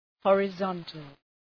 Προφορά
{,hɔ:rı’zɒntəl}